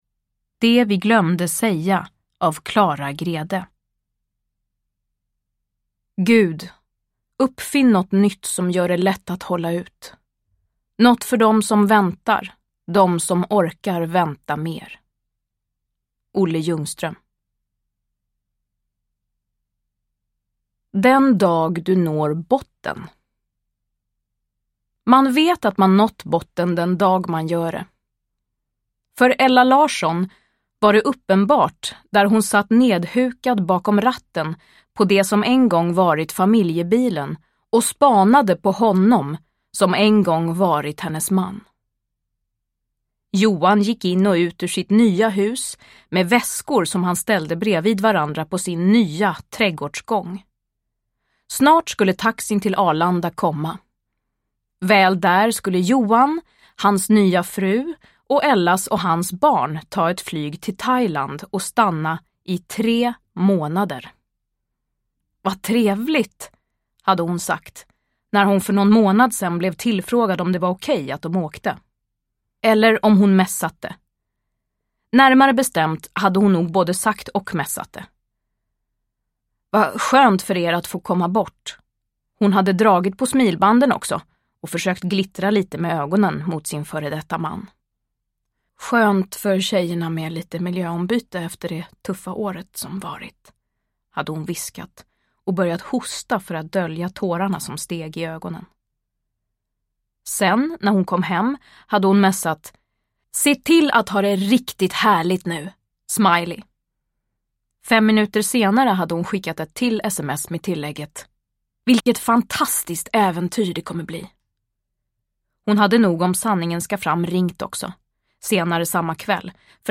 Uppläsare: Frida Hallgren
Ljudbok